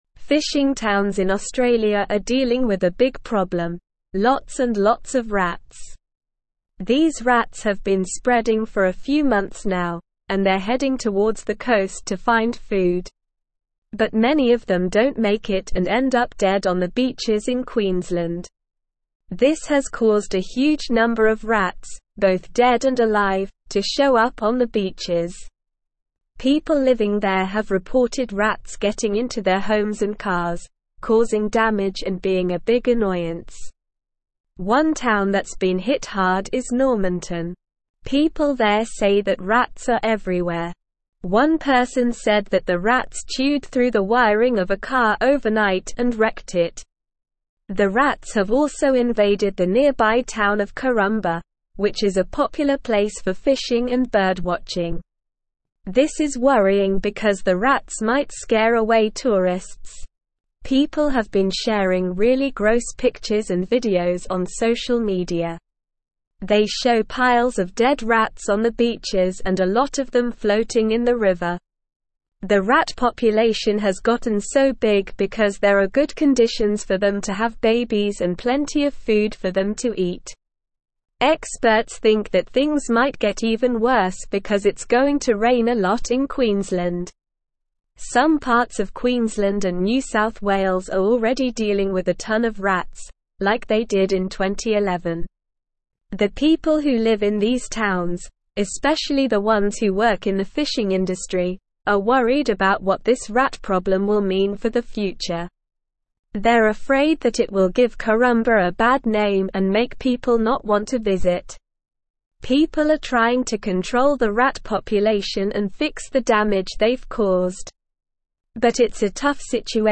Slow
English-Newsroom-Upper-Intermediate-SLOW-Reading-Rat-and-Mouse-Plague-Hits-Queenslands-Fishing-Towns.mp3